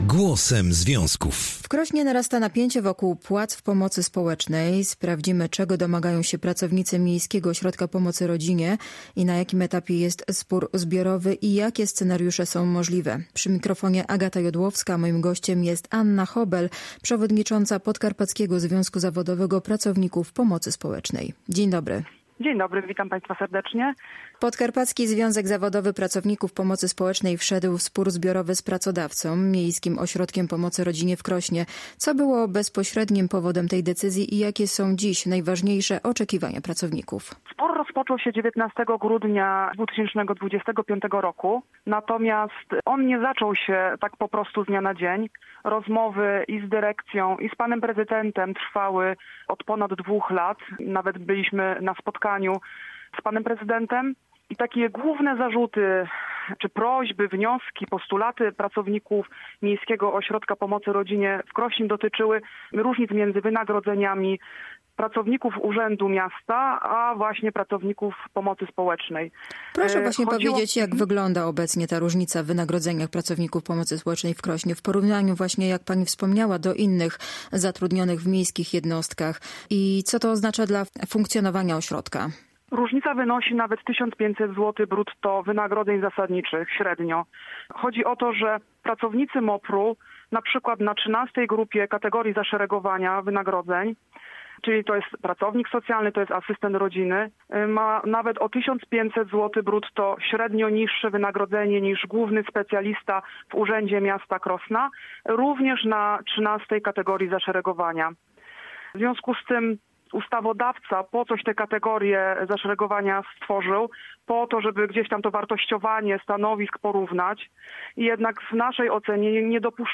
Całej rozmowy można posłuchać tutaj: